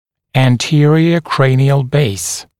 [æn’tɪərɪə ‘kreɪnɪəl beɪs][эн’тиэриэ ‘крэйниэл бэйс]переднее основание черепа